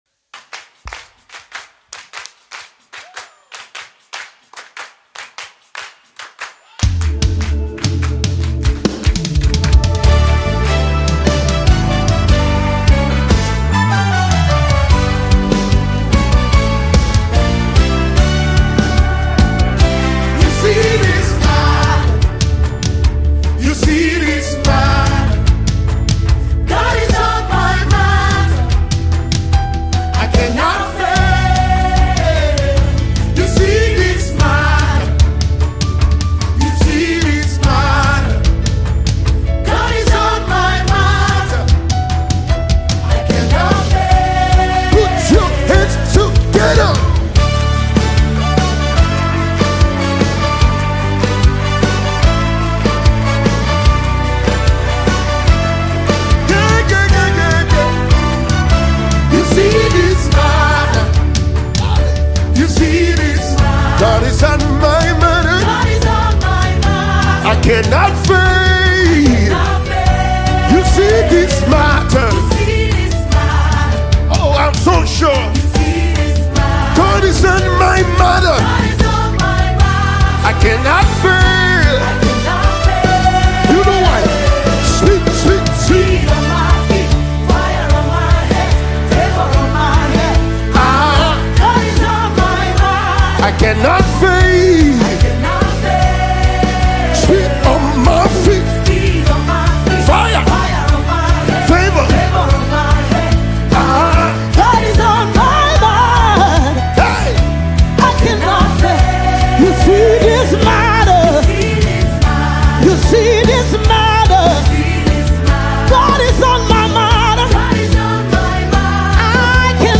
song of praise
worship song